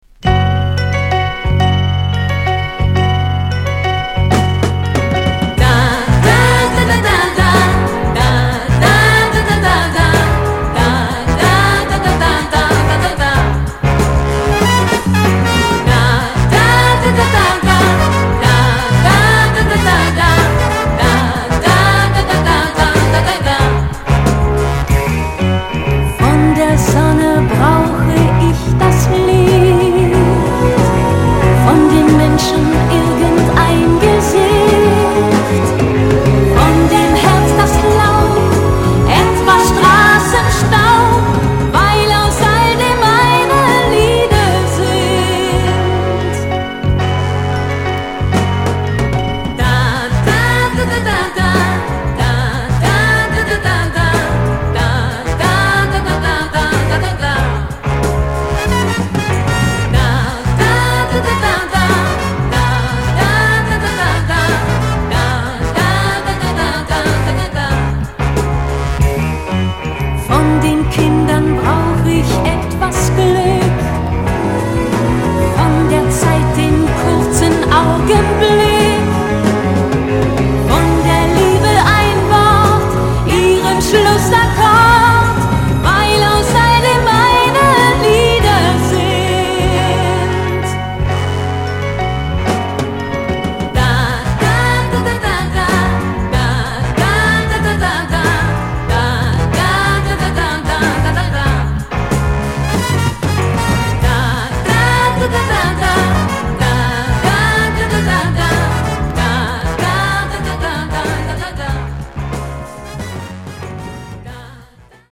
Soft Rock germany
優しく心地良い歌声と華やかに彩りを添えるオーケストラも素晴らしい作品です。